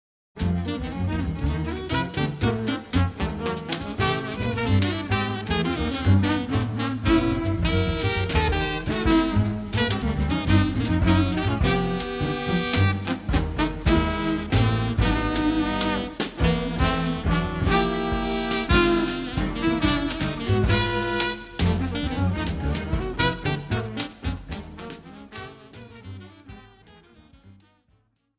Wow, we play this faster than I remembered.